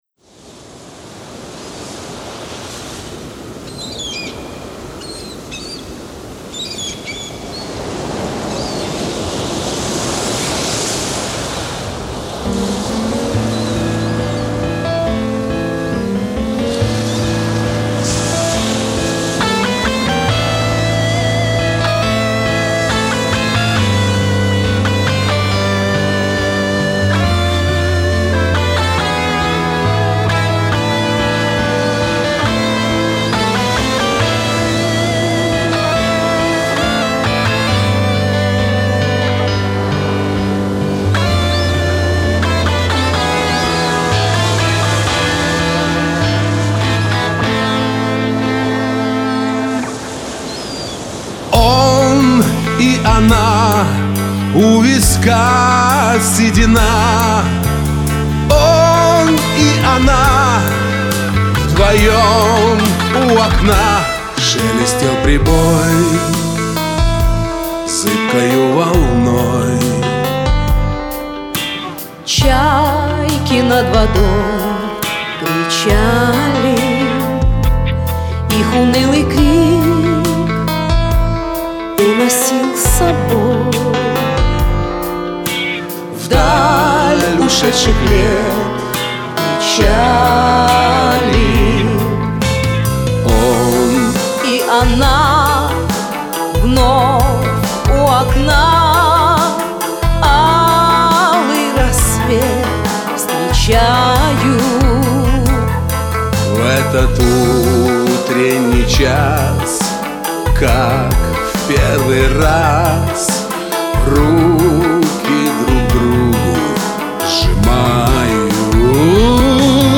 Композиция звучит как концертная!klass